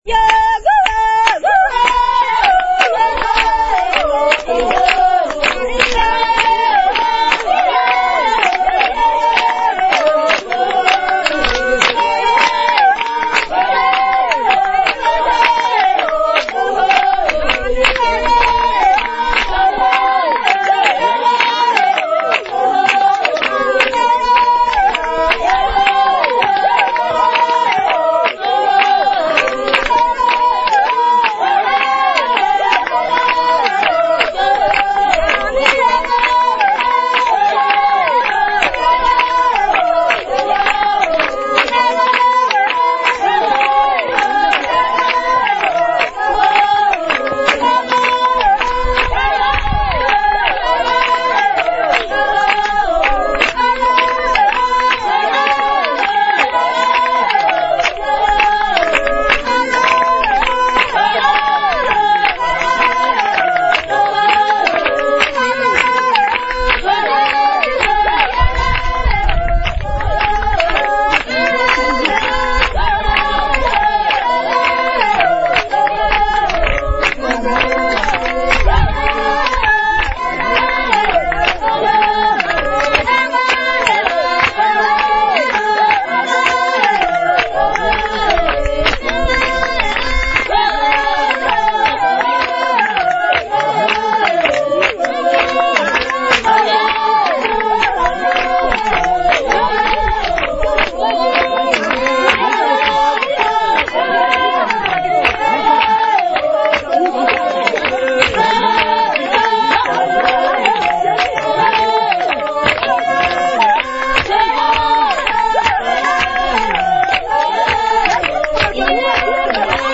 Bei den Buschmann der Kalahari in Namibia
Sie wurden mit einem einfachen Diktiergerät mitgeschnitten: